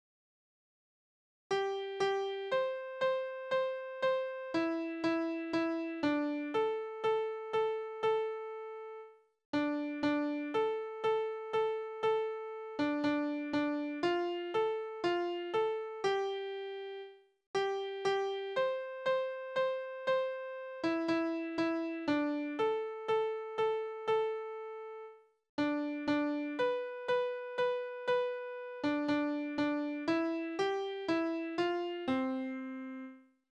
Hamburger Melodie (Kreuzpolka) Tanzverse: Kreuzpolka Tonart: C-Dur Taktart: 4/4 Tonumfang: Oktave Besetzung: instrumental